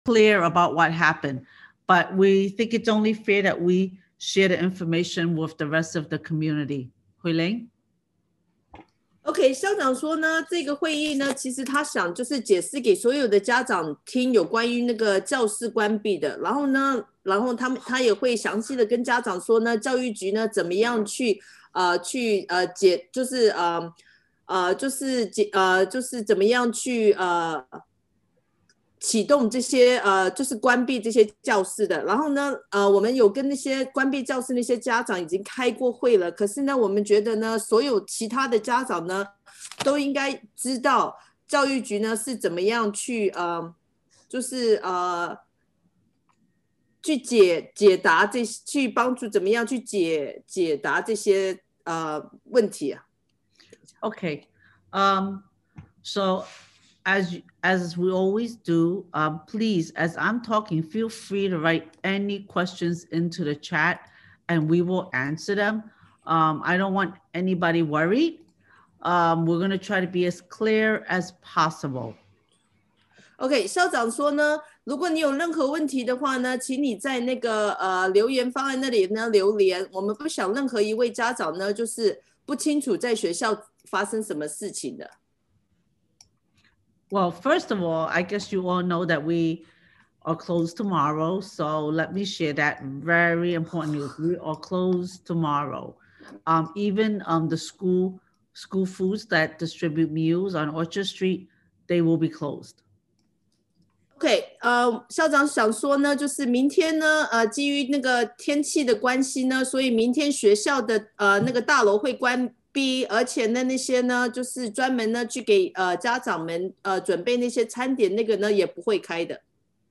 December 16th Evening Town Hall – PS42M – The Benjamin Altman School